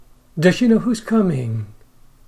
/z/ (followed by /ʃ/) becomes /ʃ/